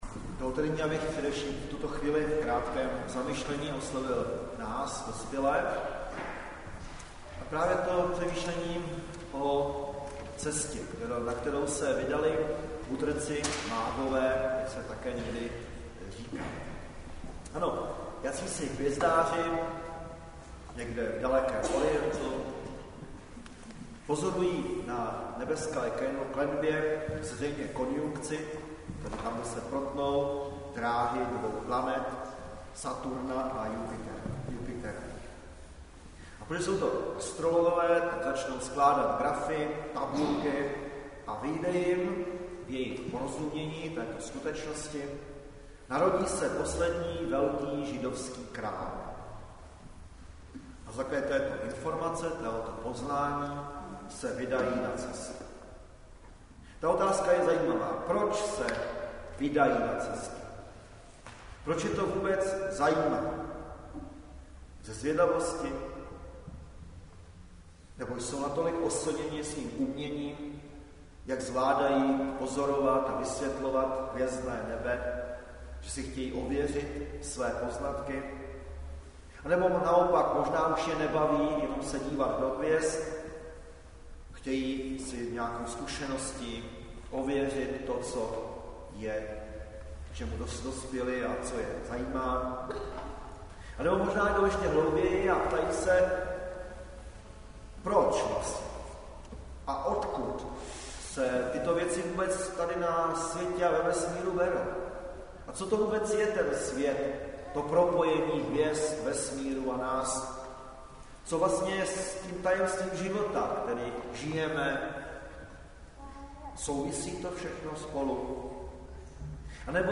Záznam kázání z bohoslužeb o 3. adventní neděli spojených s dětskou vánoční slavností a slavnostním skautským slibem dětí z klubu Kavalírů.